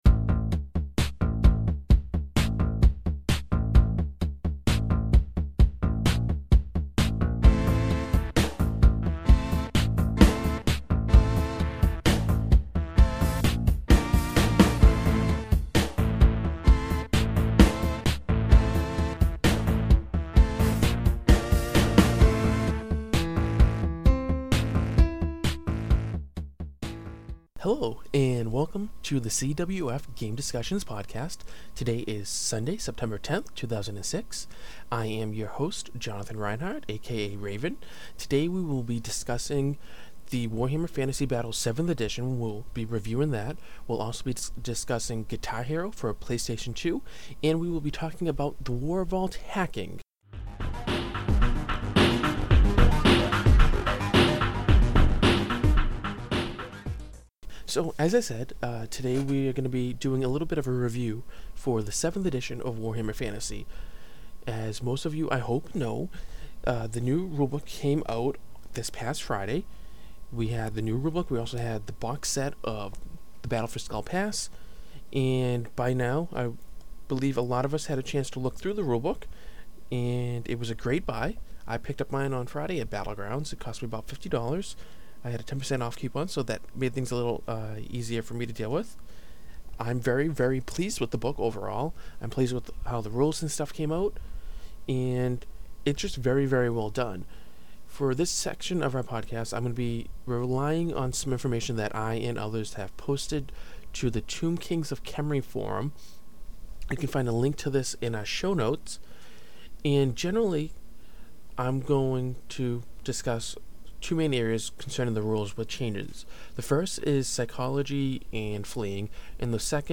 CWF-2006-09-10 Solo-Cast w/ WFB 7th Ed Review, Guitar Hero, & Warvault Hacking | Wargaming Recon